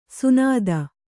♪ sunāda